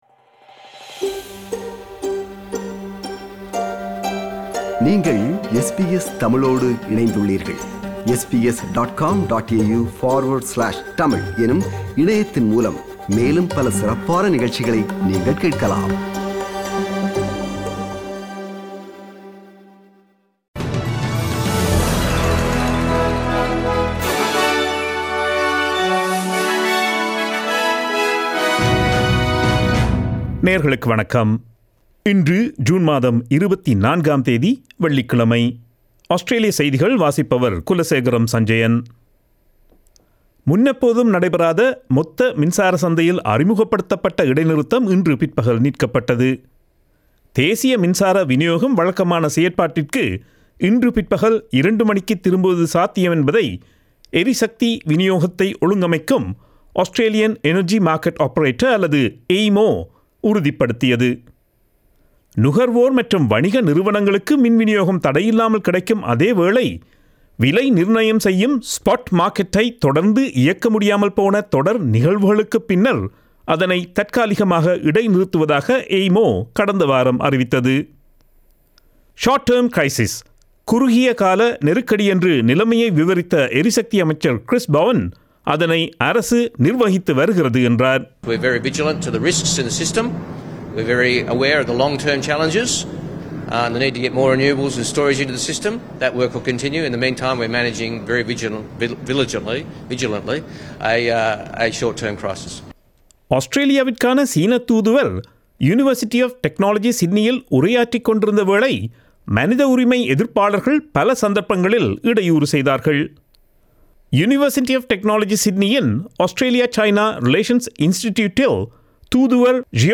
Australian news bulletin for Friday 24 June 2022.